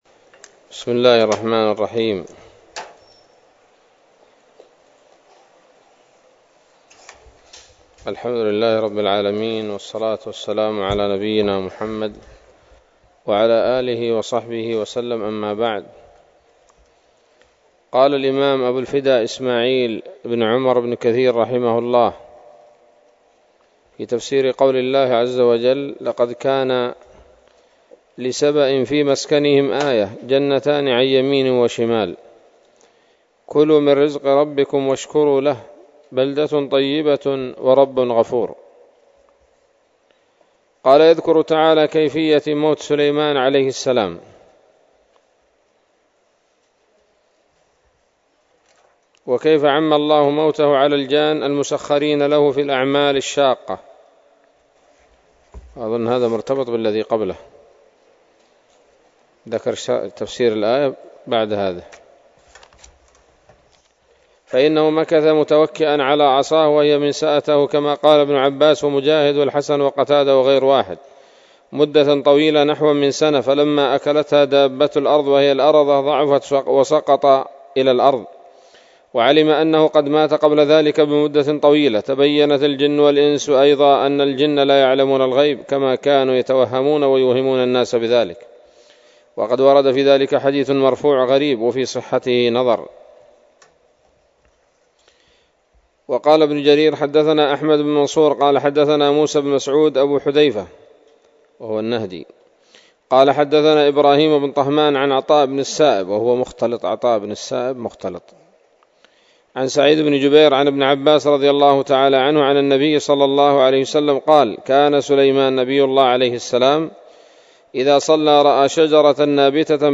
الدرس الخامس من سورة سبأ من تفسير ابن كثير رحمه الله تعالى